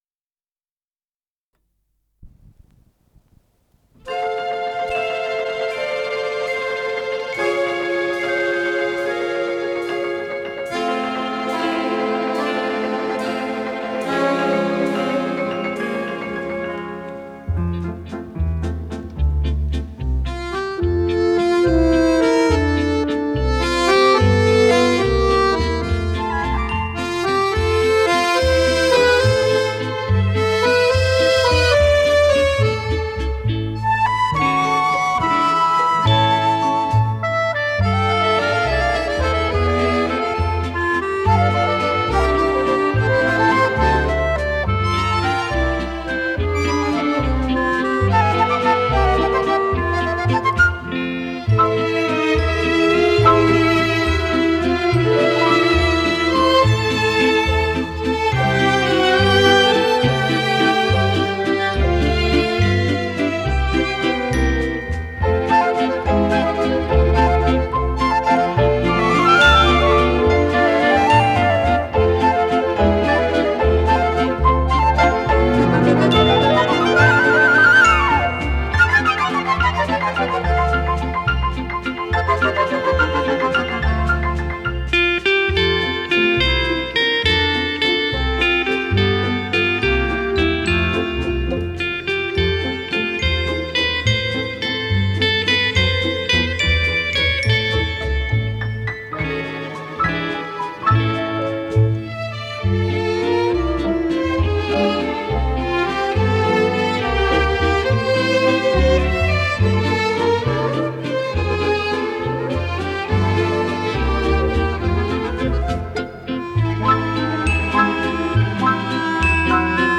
ПодзаголовокЗаставка, фа мажор
ВариантДубль моно